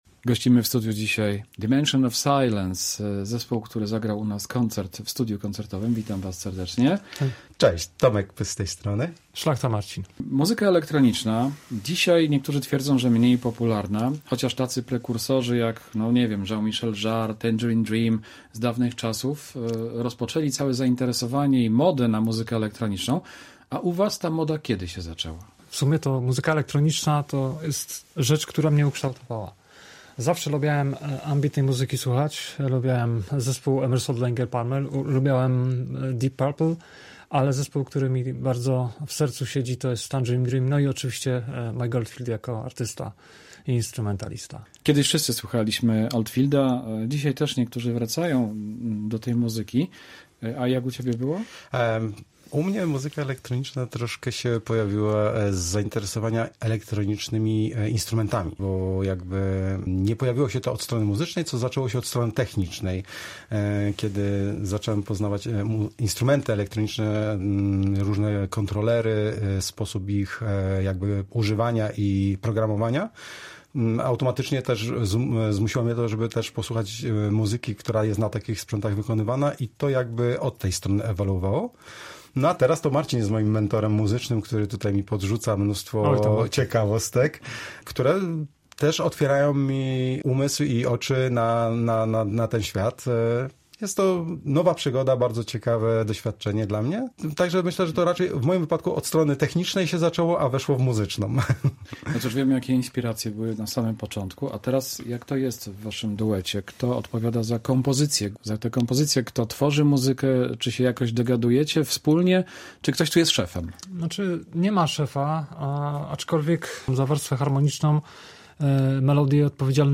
Muzycy opowiedzieli o swojej twórczości i debiutanckiej płycie w rozmowie dla Polskiego Radia Rzeszów.
Rozmowa w Polskim Radiu Rzeszów